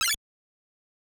flap.wav